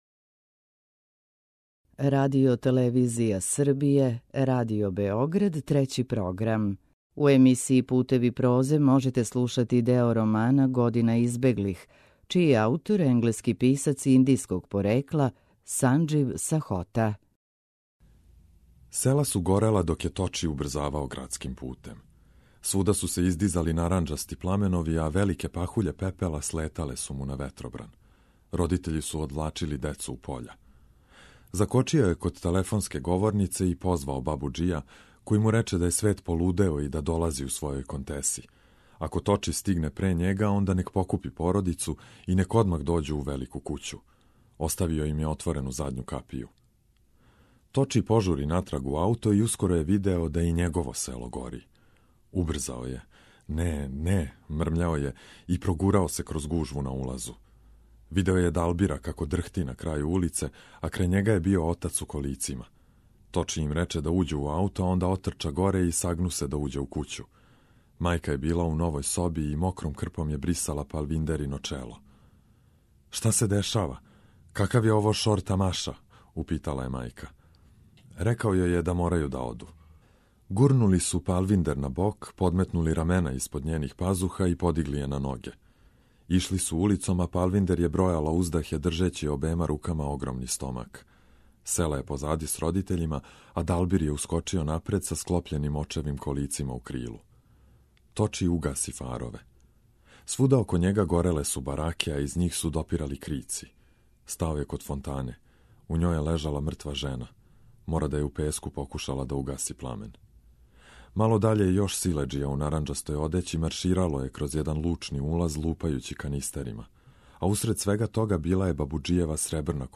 У циклусу Путеви прозе ове недеље можете слушати део романа „Година избеглих”, чији је аутор британски писац индијског порекла Санџив Сахота.